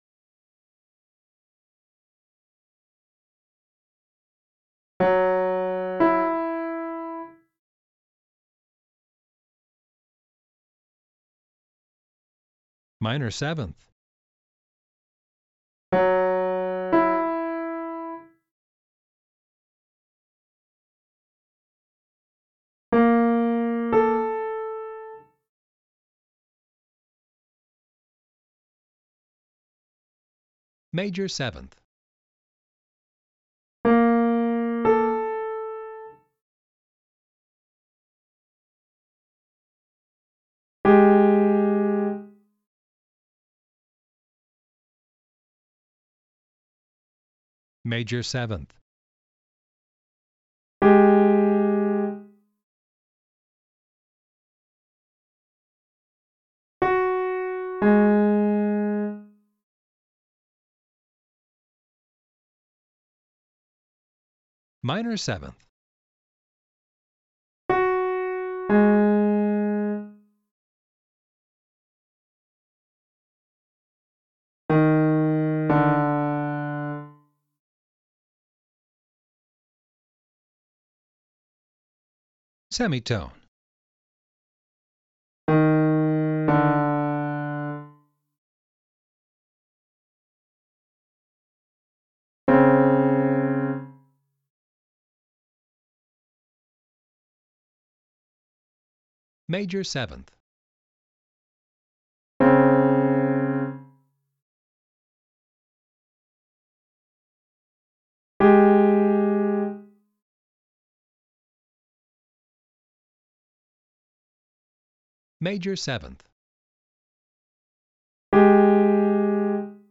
This module lets you practice the intervals in each of the three forms and also in combinations of the forms: “melodic” includes ascending and descending, and “mixed” includes all three.
Once you think you’re getting a sense of each interval’s sound, listen to the corresponding “Test” tracks, which include a short pause after each interval.
You’ll hear the correct answer so you know if you got it right and have the chance to hear the interval again.
Test-5.-STTm7M7-mixed.mp3